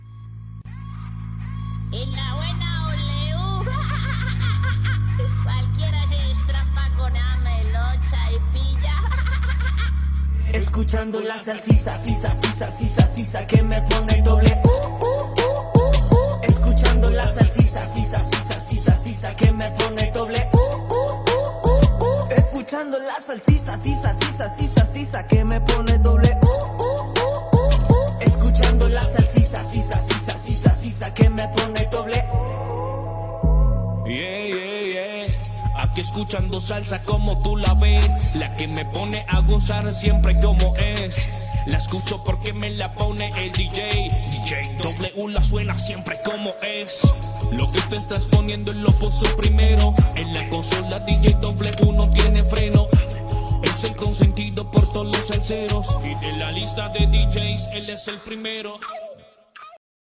Indicatiu cantat de la ràdio